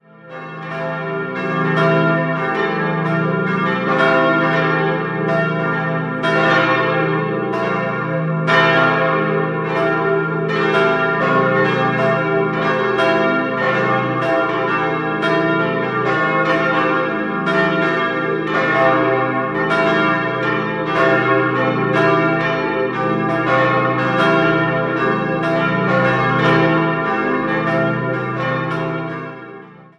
Das historische Kirchengebäude wurde bei einem Luftangriff 1945 total zerstört und nach dem Krieg in moderneren Formen wieder aufgebaut. 4-stimmiges Geläute: c'-e'-g'-a' A lle Glocken wurden 1947 von der Gießerei Junker in Brilon gegossen.